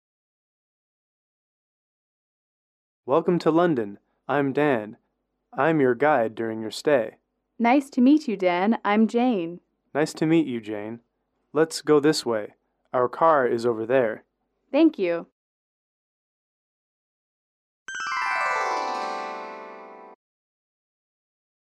英语主题情景短对话19-1：与导游见面(MP3)